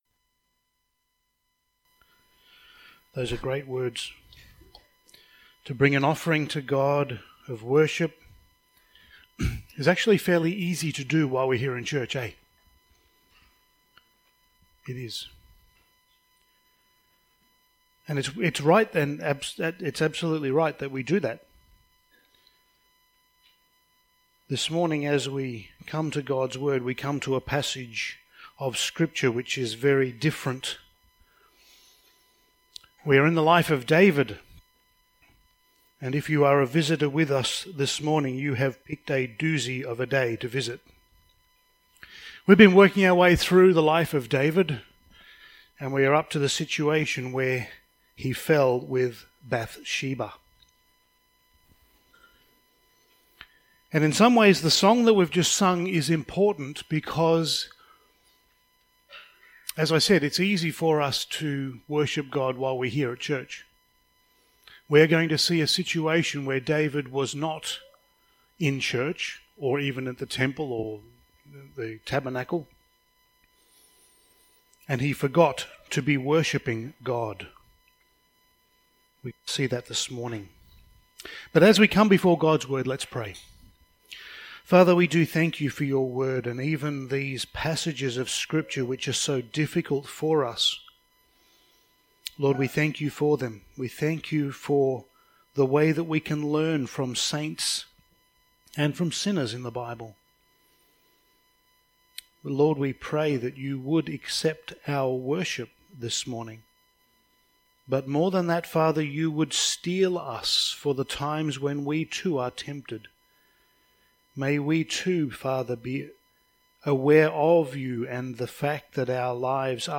Passage: 2 Samuel 11:1-27 Service Type: Sunday Morning